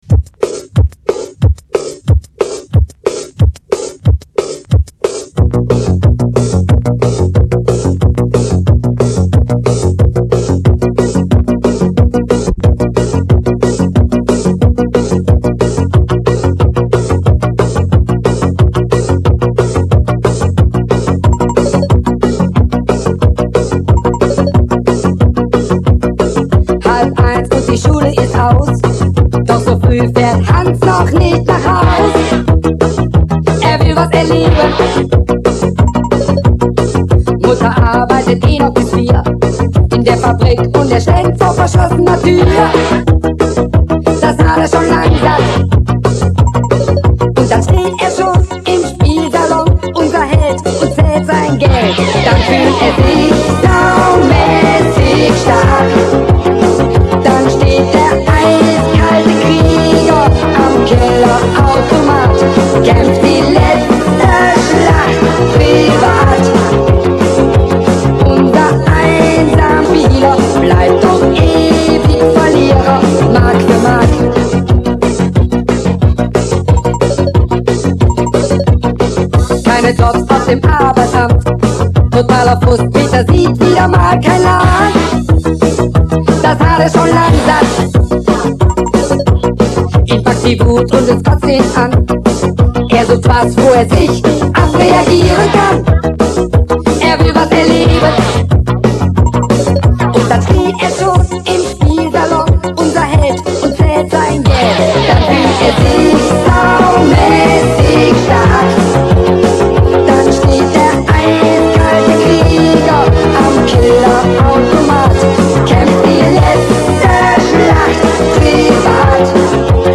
Unvollständig
Von Audiokassette